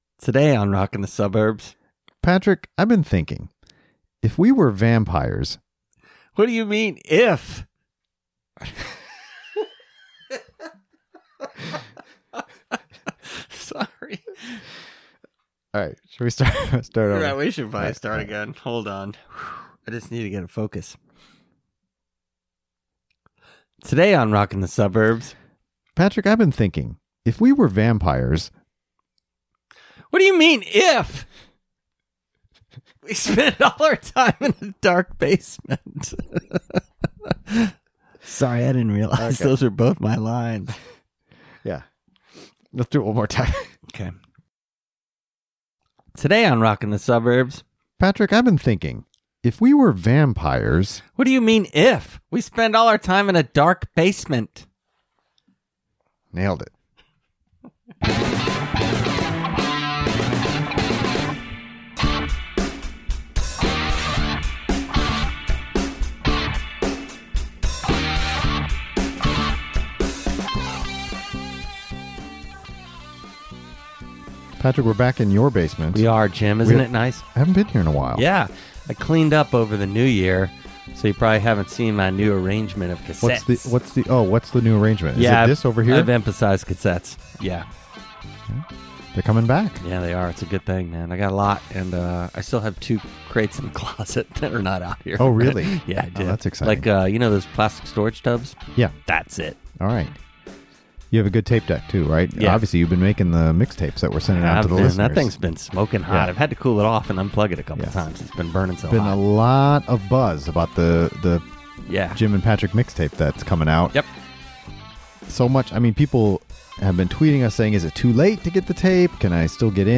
When we asked our listeners to tell us about their favorite music of 2017, one artist received the most mentions. And so we present four calls that reference Jason Isbell.